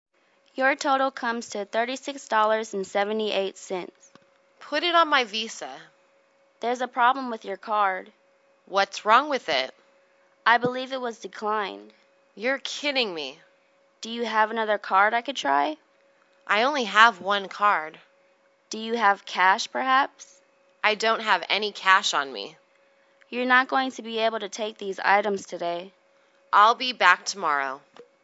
银行英语对话-Your Debit Card(1) 听力文件下载—在线英语听力室